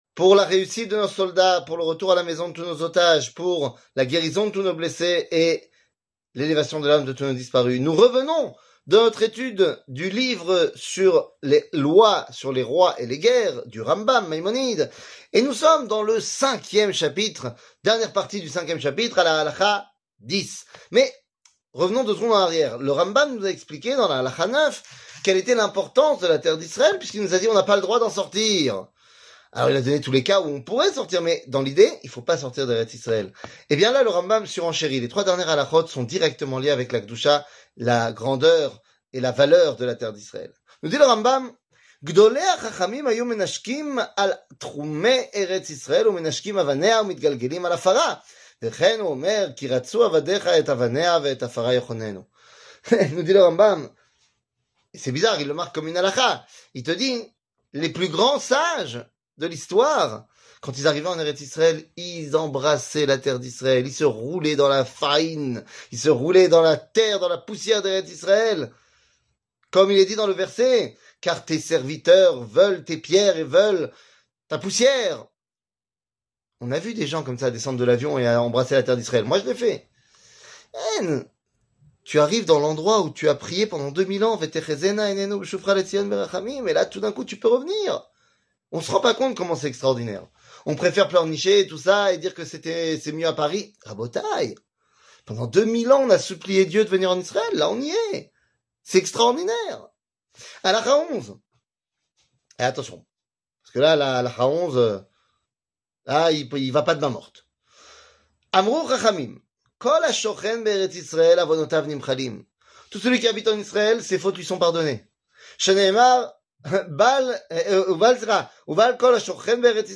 שיעור מ 06 דצמבר 2023